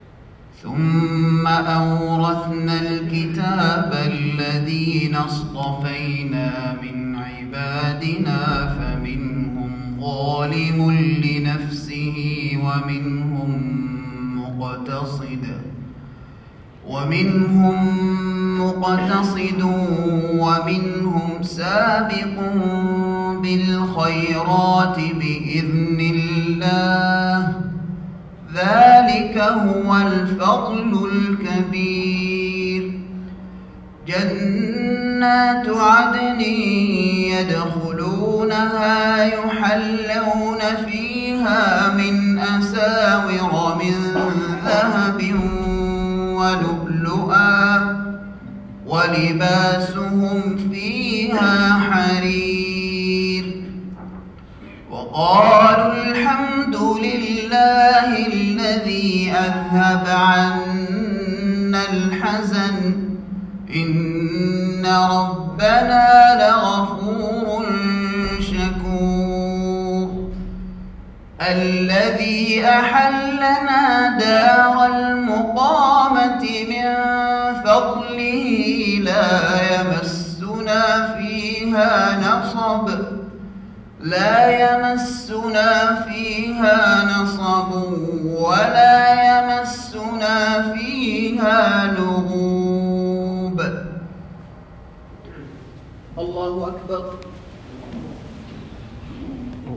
تلاوة من سورة فاطر